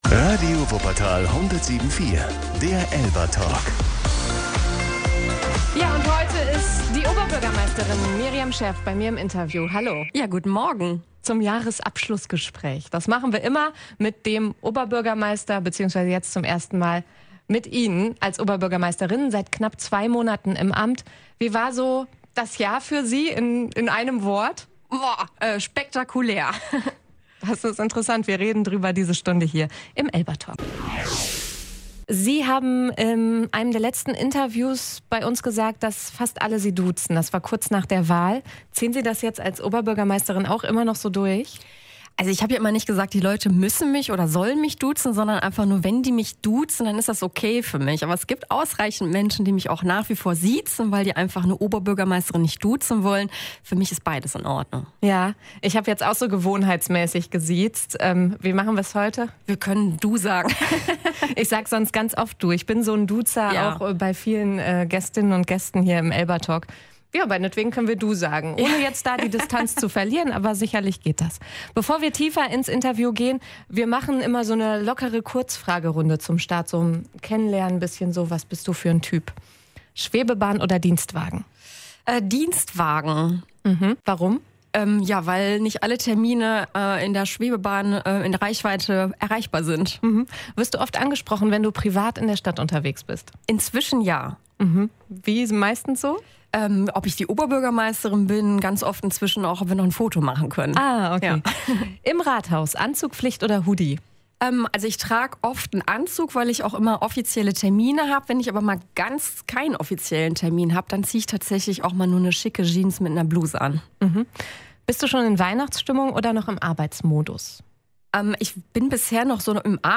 Im ELBA-Talk spricht Wuppertals Oberbürgermeisterin Miriam Scherff über ihre ersten knapp zwei Monate im Amt. Sie beschreibt das Jahr als spektakulär und sagt, es habe sich angefühlt wie ein Galopp.